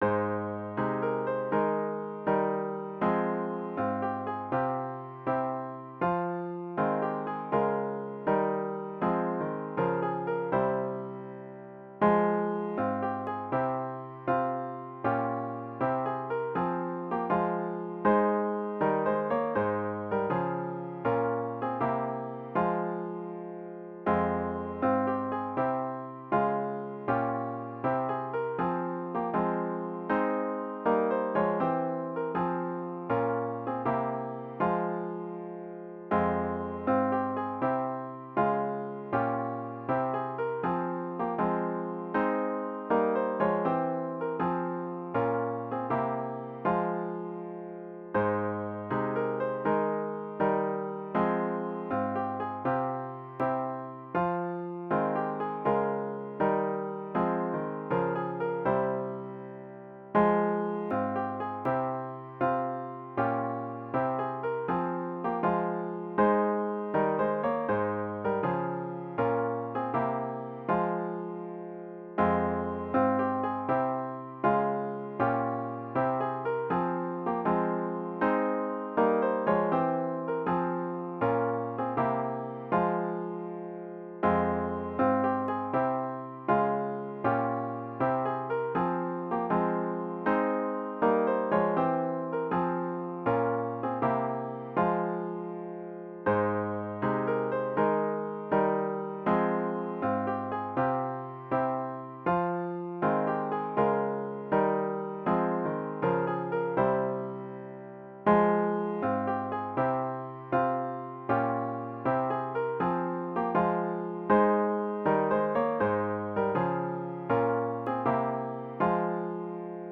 *HYMN “Silence! Frenzied, Unclean Spirit” GtG 181